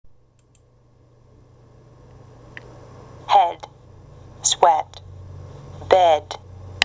[ エ ] head, sweat, bed